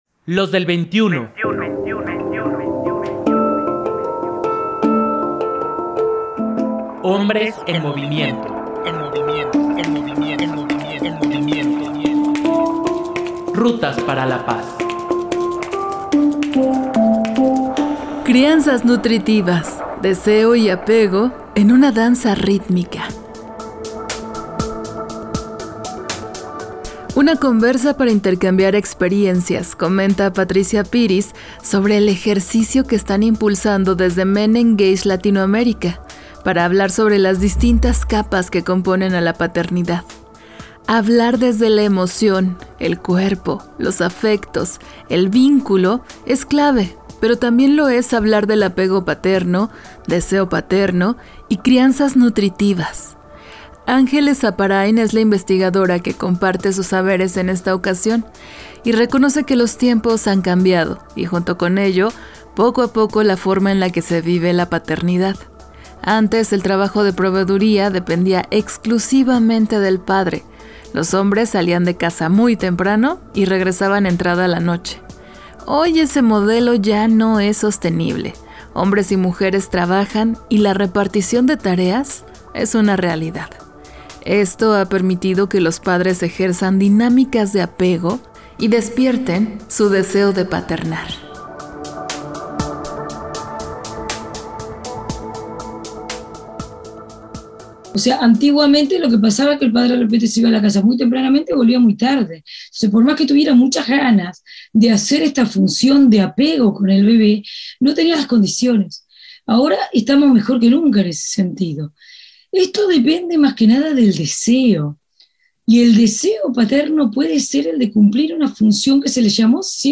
Una conversa para intercambiar experiencias